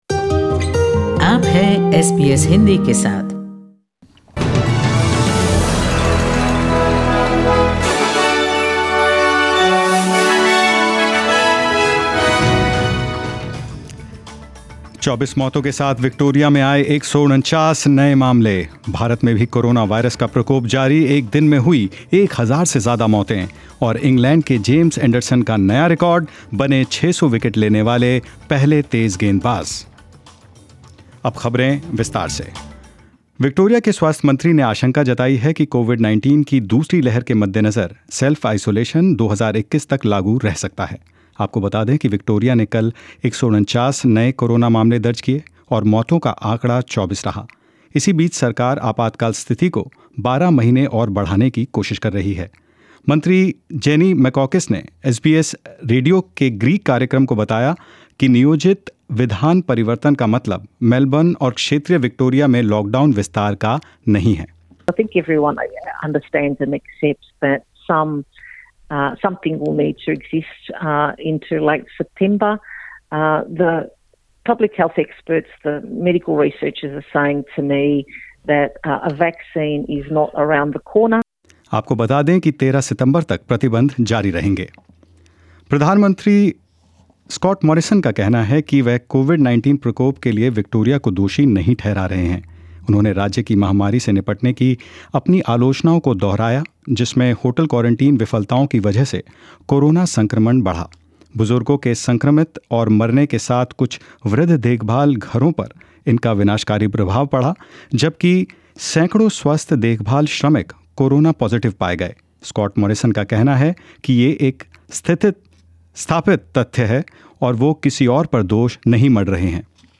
Hindi News 26th August 2020